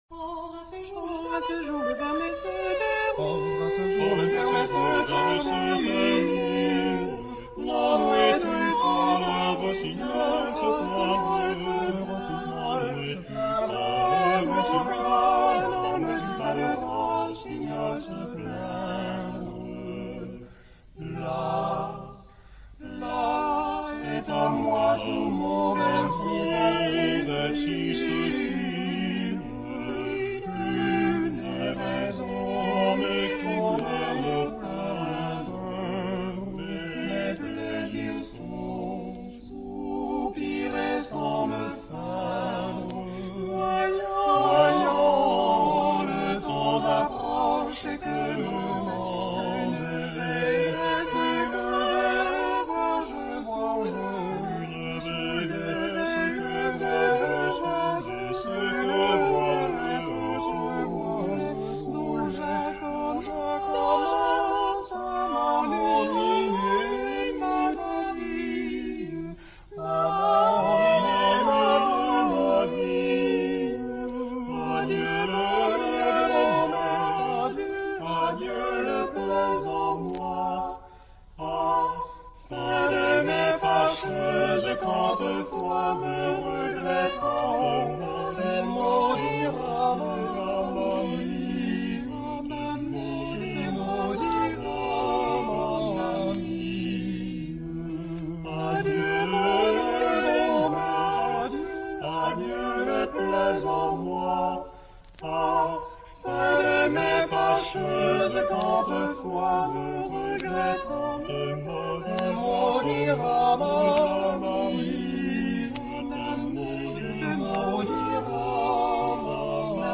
Genre-Style-Forme : Renaissance ; Chanson ; Profane
Type de choeur : SATB  (4 voix mixtes )
Tonalité : mi mineur